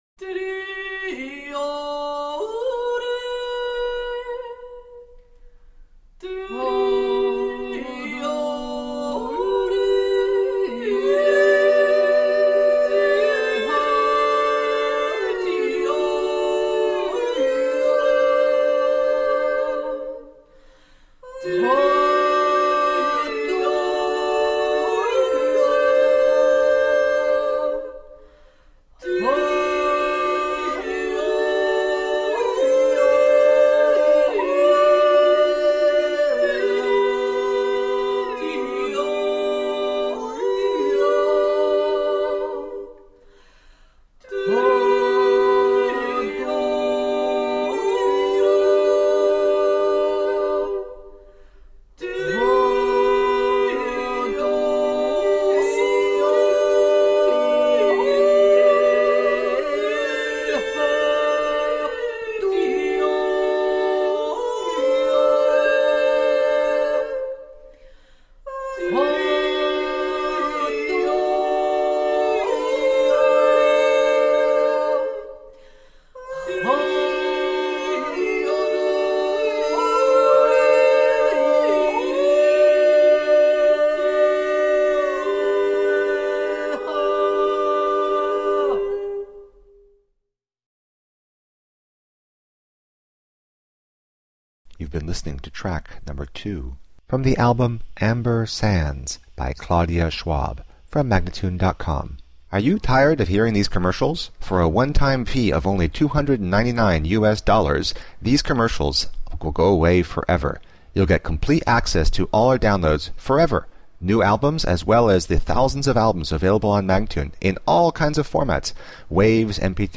Tagged as: World, Folk, World Influenced